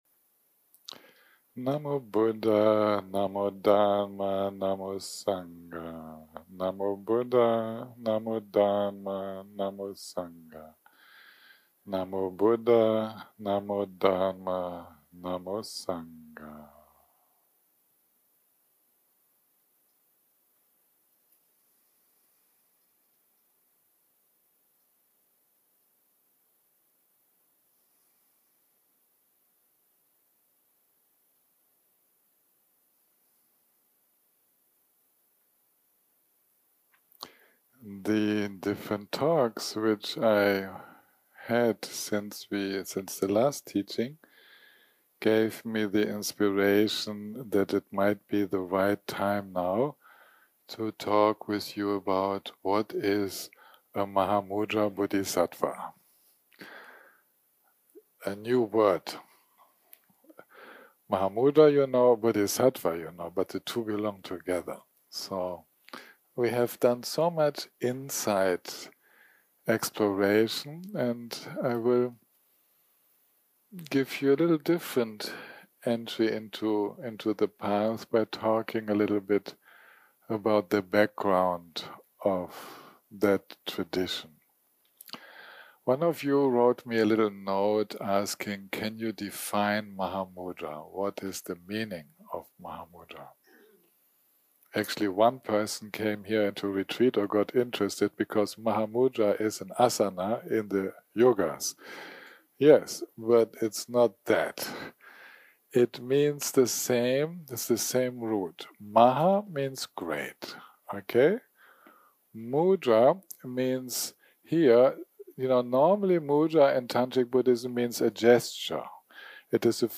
type: Dharma Talks